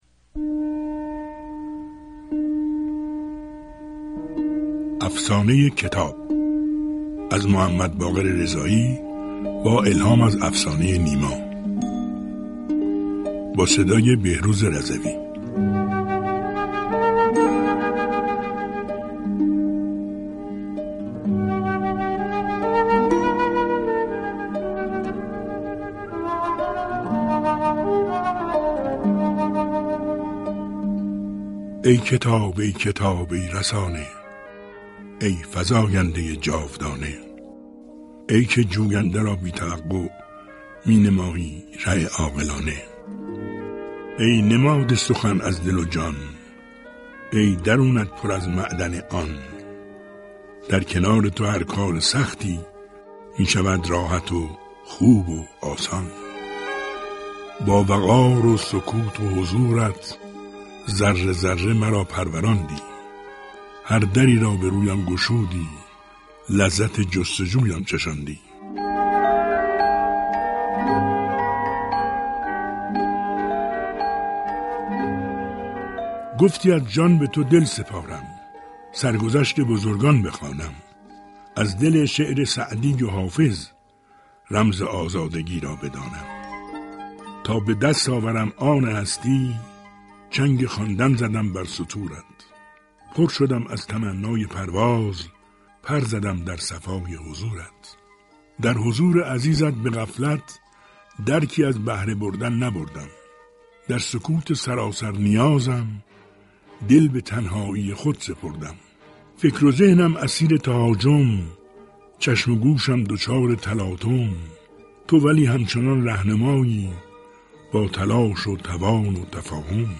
«كتاب شبِ» رادیو در آستانه سی سالگی/ شعری با صدای بهروز رضوی+صوت
برنامه «كتاب شب» رادیو كه به‌زودی سی سالگی‌اش را جشن می‌گیرد، به بهانه هفته كتاب و كتابخوانی شعری در زمینه كتاب با روایت بهروز رضوی آماده پخش كرده است.